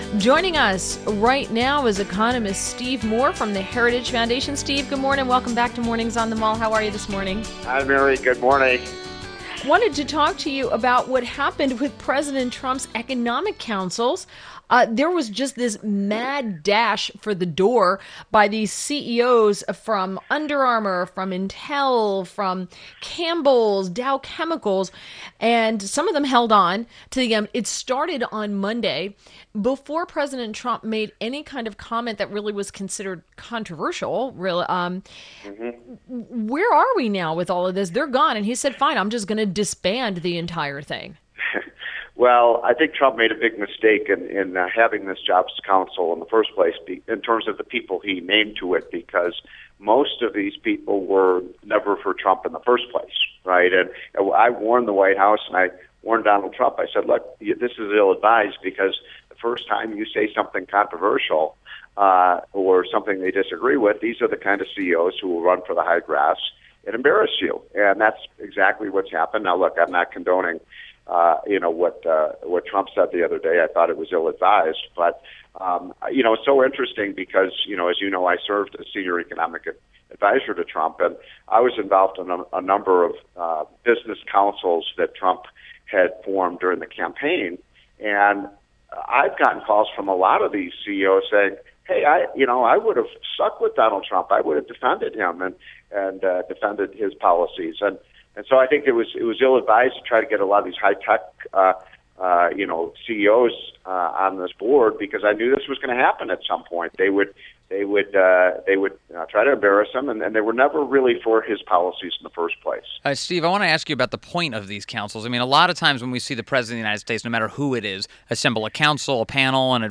INTERVIEW – STEVE MOORE – Economist at The Heritage Foundation